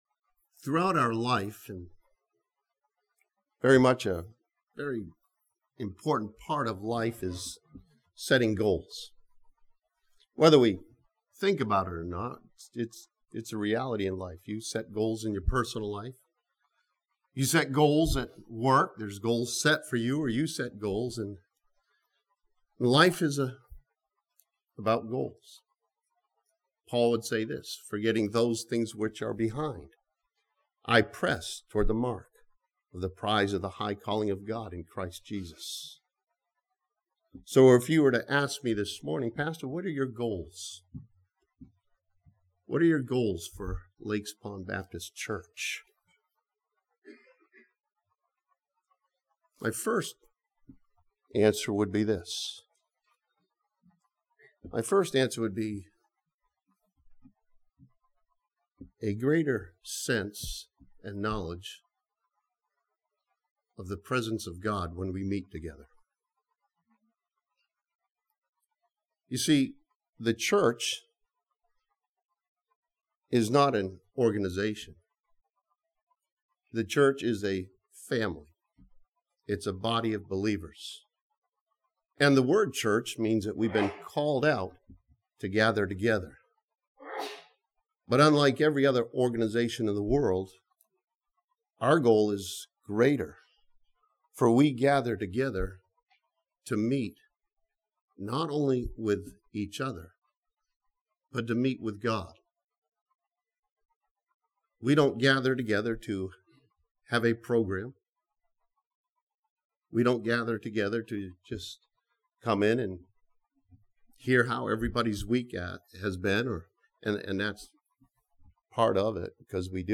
This sermon from 1 John chapter 4 challenges believers to have the goal of nothing short of love for one another.